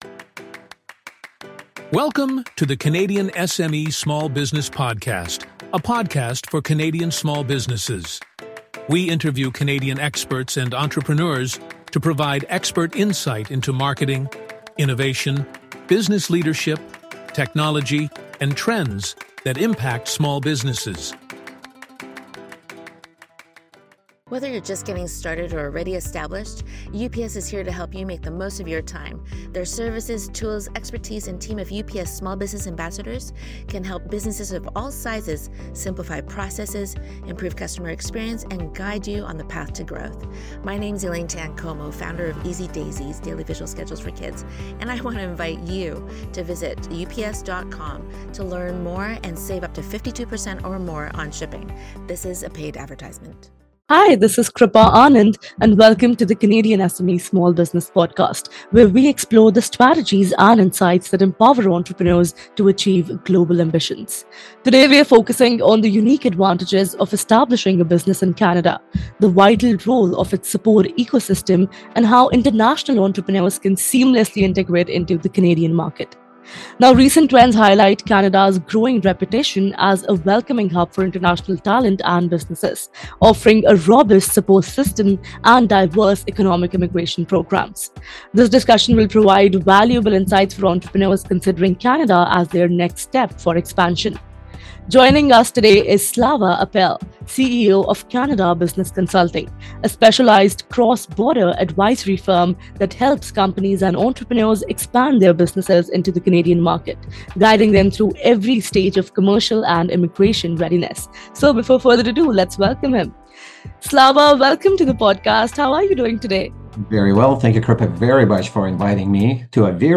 podcast-global-business-expansion-2025-10-01.mp3